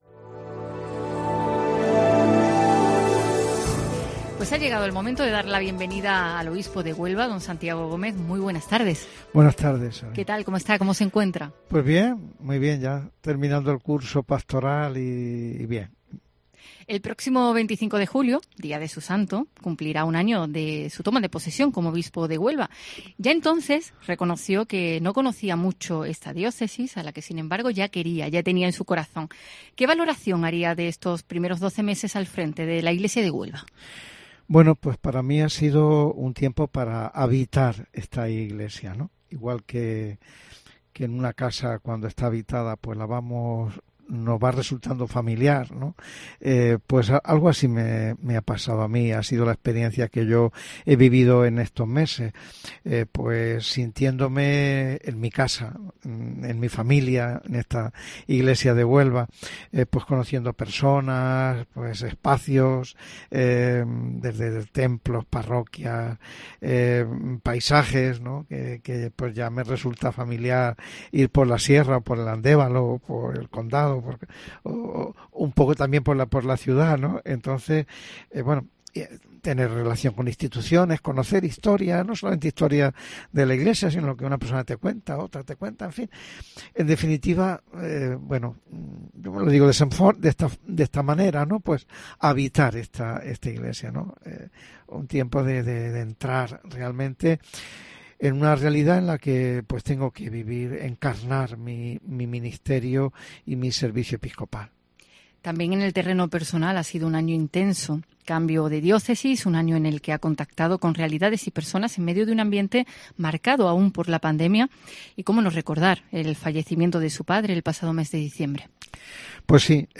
Entrevista a Santiago Gómez en el primer aniversario como obispo de la Diócesis de Huelva